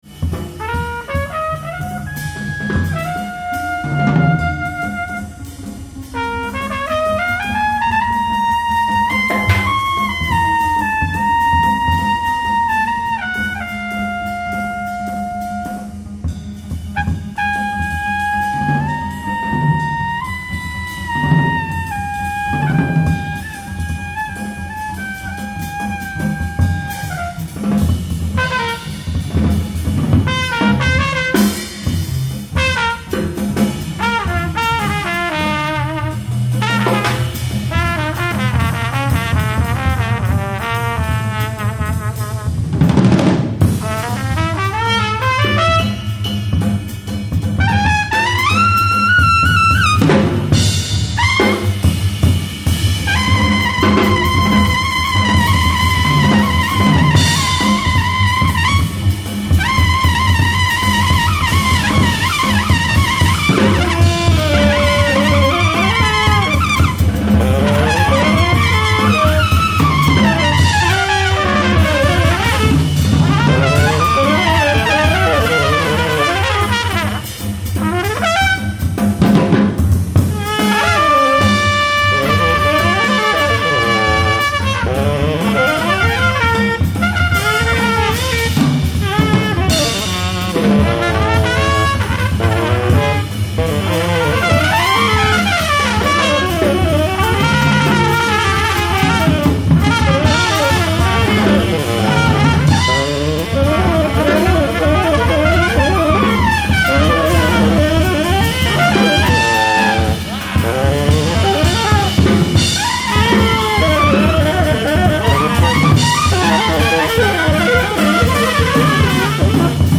Recorded in concert at the Vision Festival, The Center,